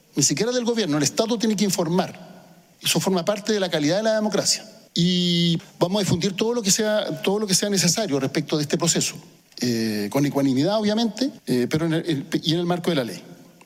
El ministro Secretario General de la Presidencia, Álvaro Elizalde, fue invitado al Consejo Constitucional, donde se refirió al anteproyecto redactado por la Comisión Experta y al rol que tendrá el Ejecutivo en este proceso.